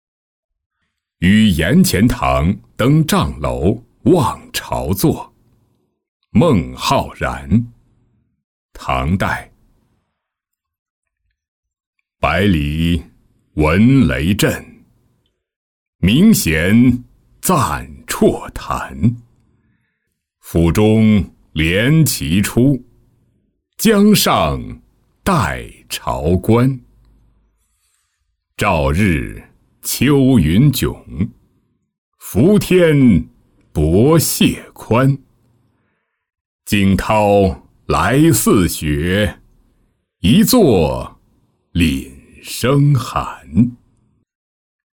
与颜钱塘登障楼望潮作-音频朗读